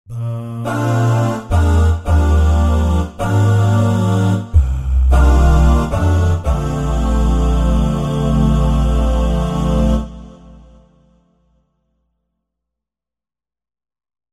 Bahs Guys demo =3-D04.mp3